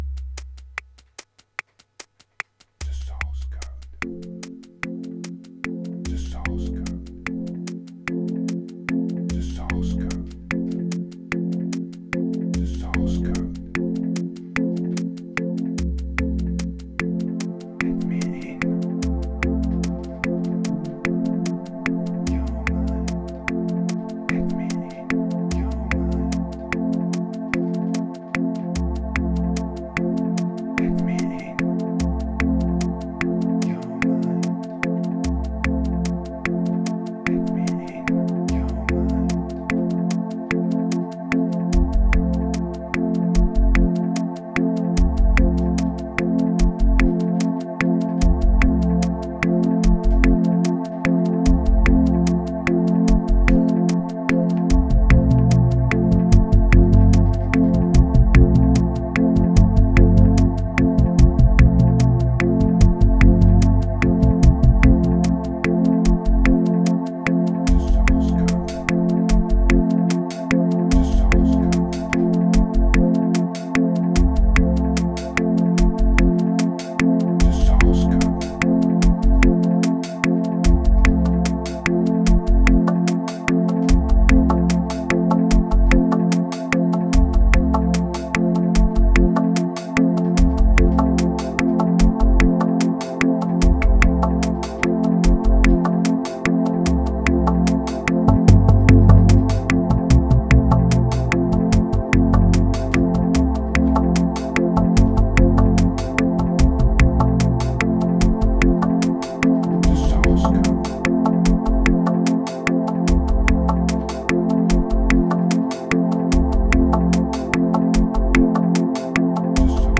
Ambient Chillout Hush Riddim Moods Chords